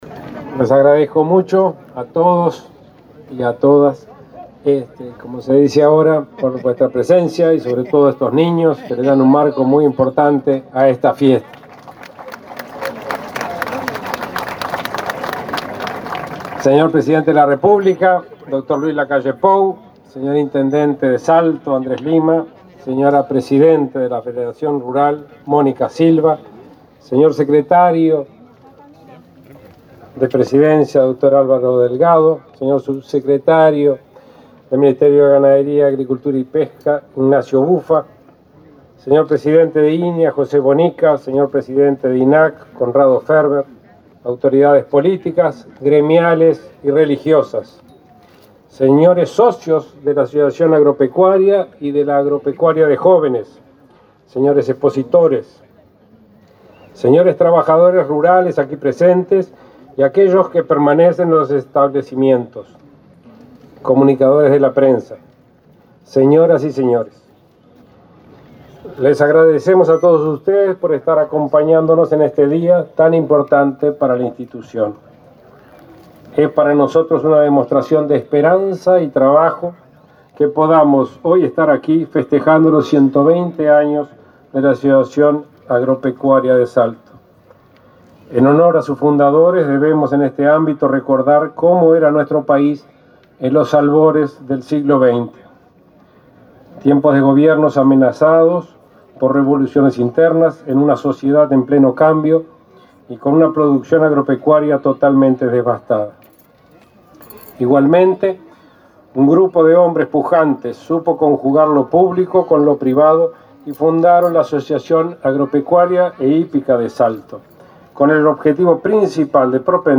Conferencia de prensa por la apertura de la Expo Salto 2021